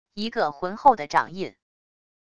一个浑厚的掌印wav音频